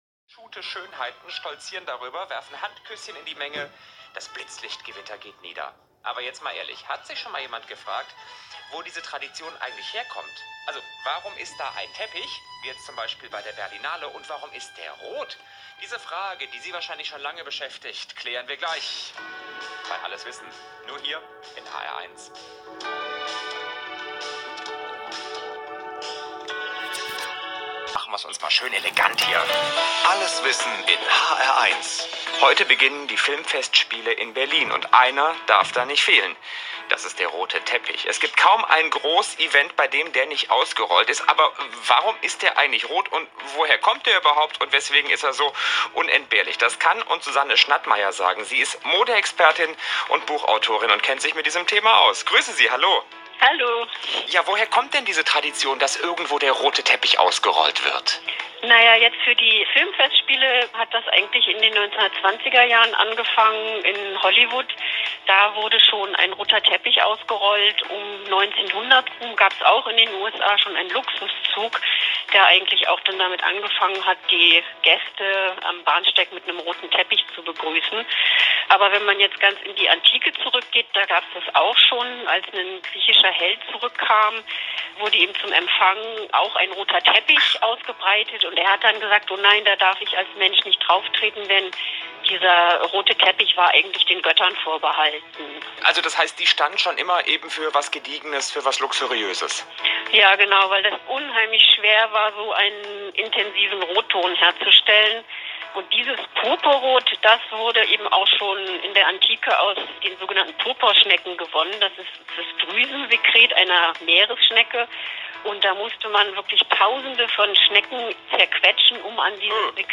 In dem Zusammenhang bin ich inzwischen im Hessischen Rundfunk (hr1) in der Rubrik „Alles Wissen“ zum Thema „Roter Teppich“ interviewt worden.